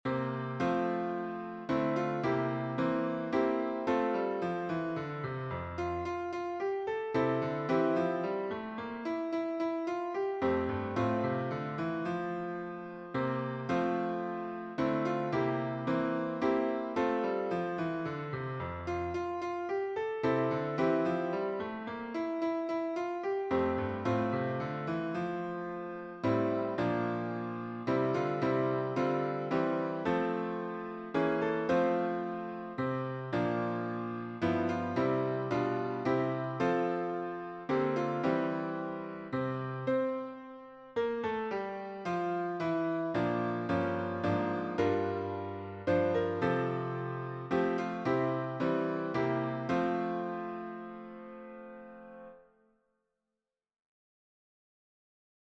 1v Voicing: S Genre: Sacred, Sacred song
Language: English Instruments: Keyboard
First published: 1990 Description: My God Shall Supply All Your Need is a gospel chorus based on Philippians 4:18-19.